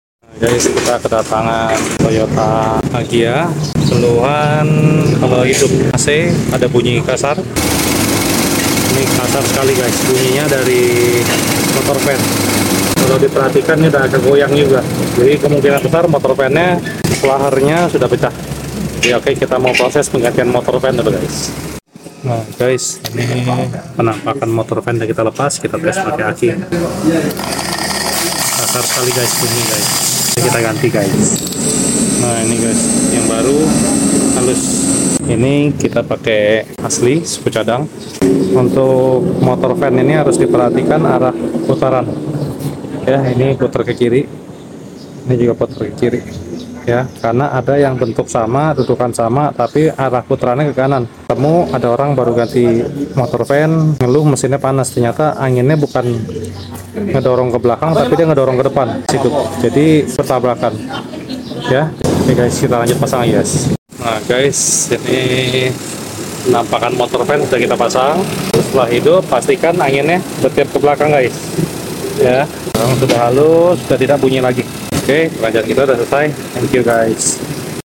Kedatangan toyota agya 1000 keluhan bunyi kasar di area mesin ketika hidup AC, ternyata bunyi tersebut berasal dr motor fan. Kita lakukan penggantian motor fan yg baru asli suku cadang. Setelah diganti, suara mesin kembali normal.